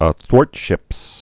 (ə-thwôrtshĭps) also a·thwart·ship (-shĭp)